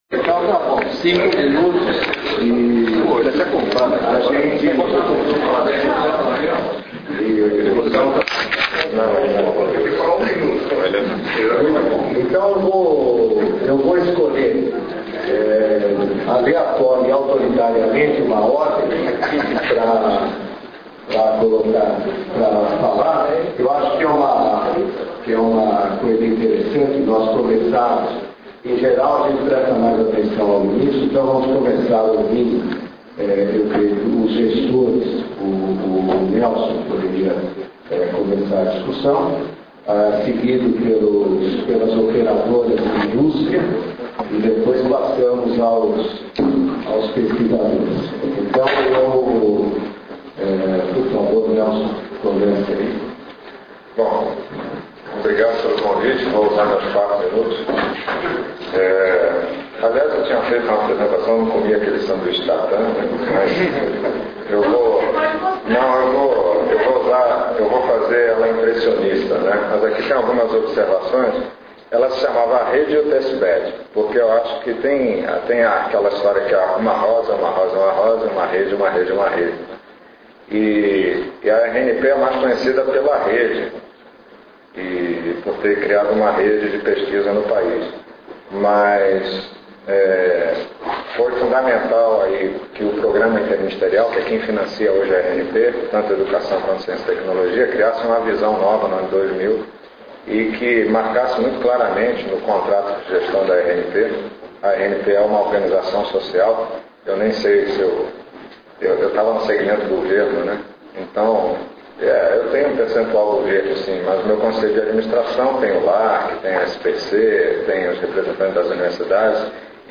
Mesa-redonda – Cont – Colloquium on Optical Network Testbeds
mesa-redonda.mp3